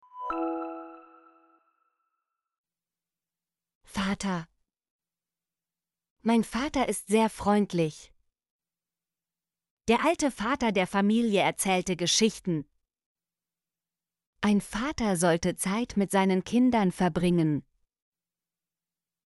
vater - Example Sentences & Pronunciation, German Frequency List